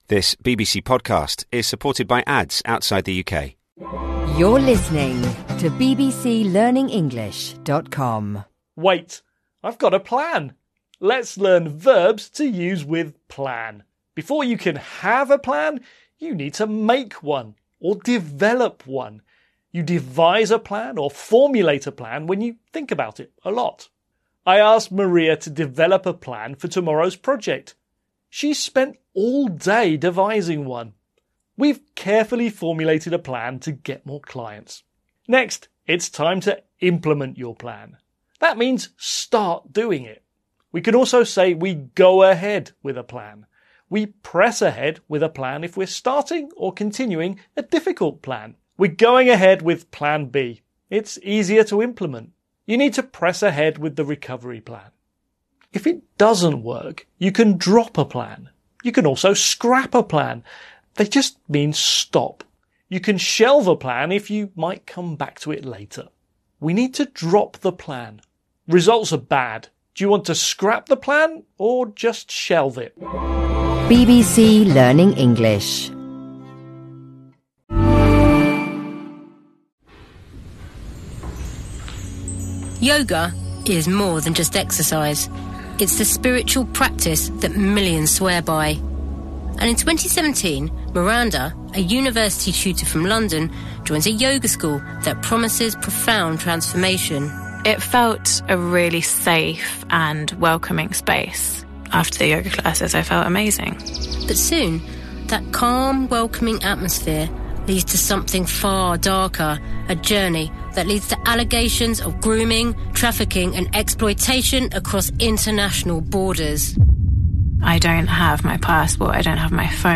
Build your English vocabulary in six minutes. Every Monday join two of our presenters and hear about different ways to develop your vocabulary knowledge and skills.